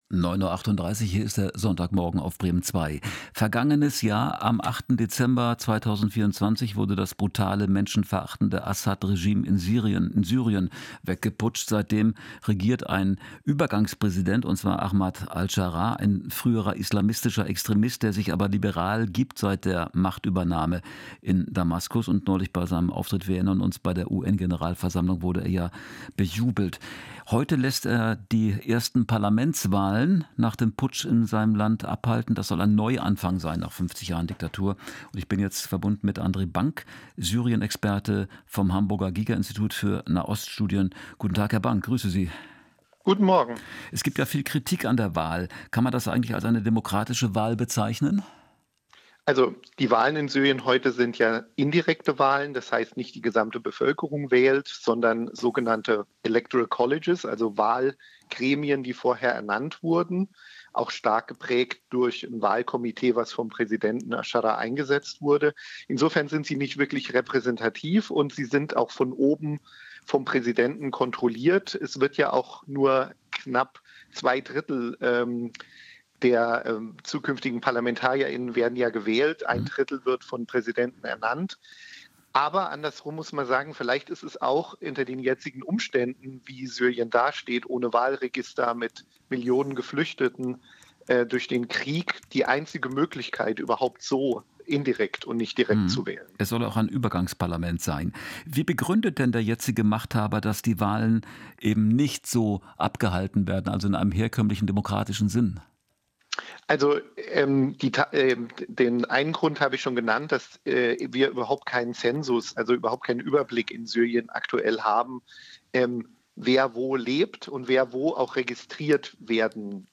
➡ Zum Interview mit Radio Bremen: